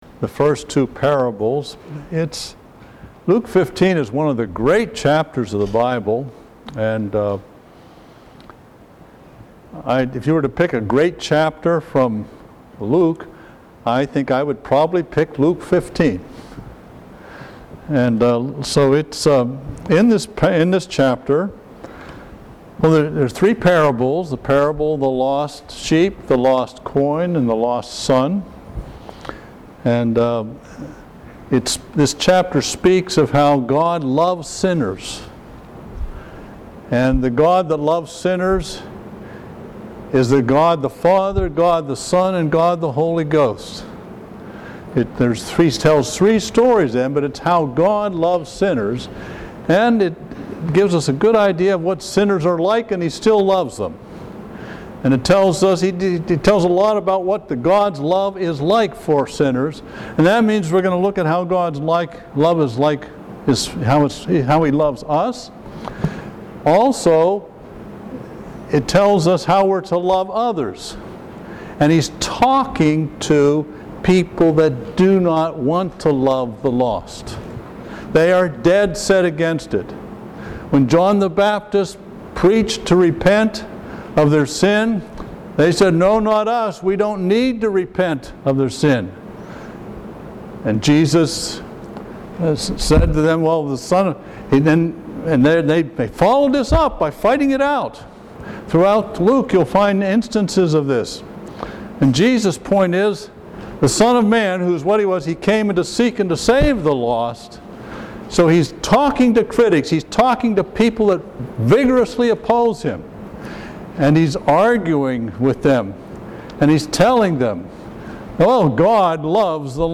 Miscellaneous Sermons